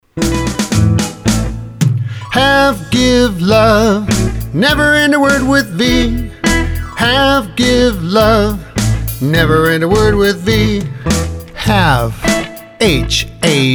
Spelling Song